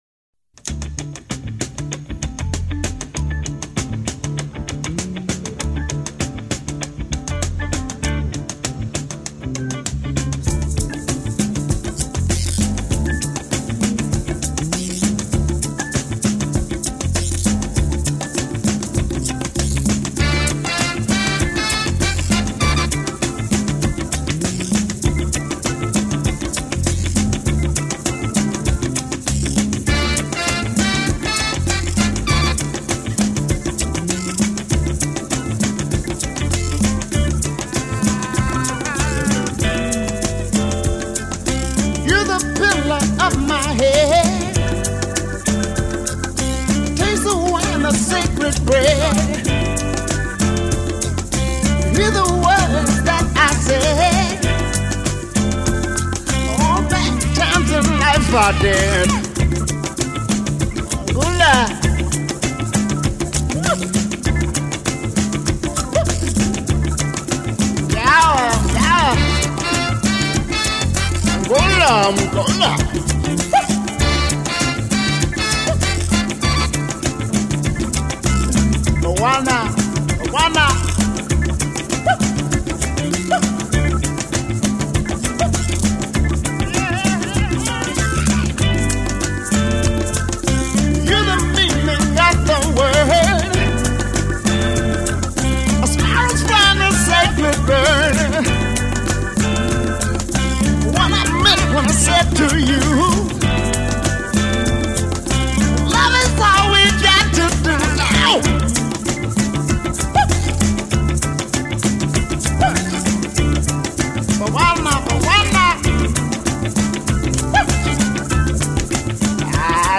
manically funky
nearly unhinged
Category: Song of the Day, Soul